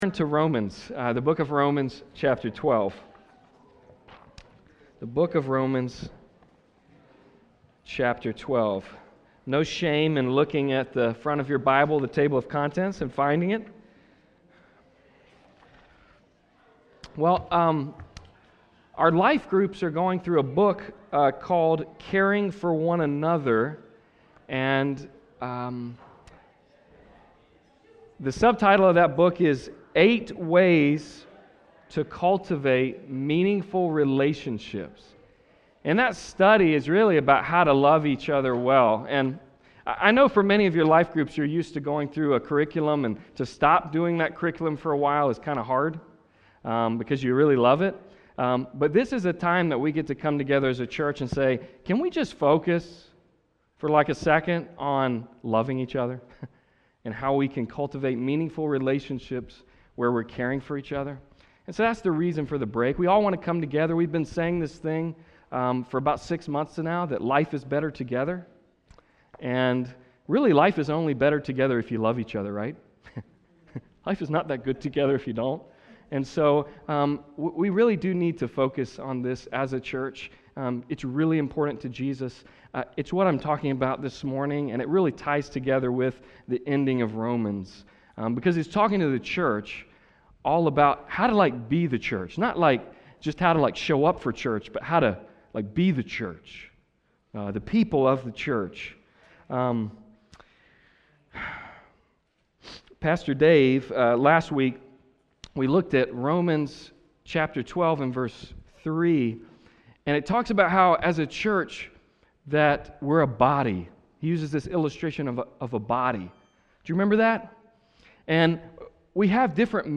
Passage: Romans 12:9-16 Service Type: Sunday Service